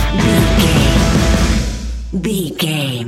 Ionian/Major
hard rock
heavy rock
distortion